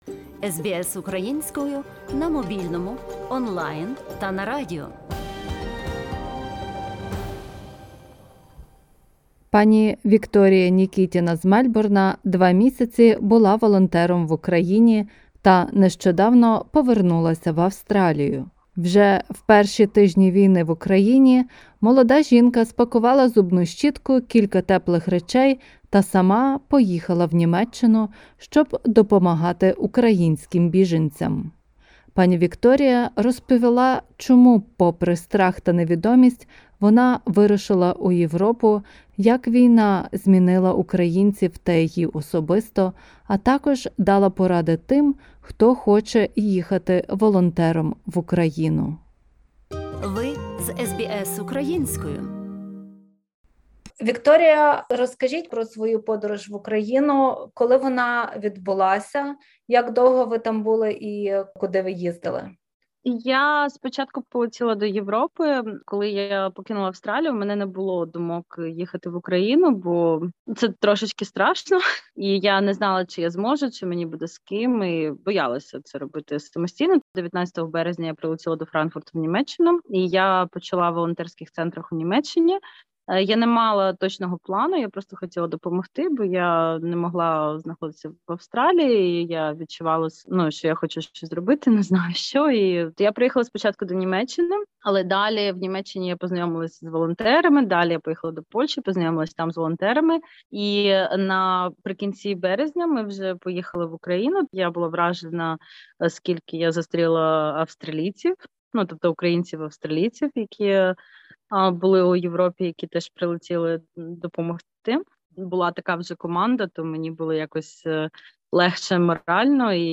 Розмова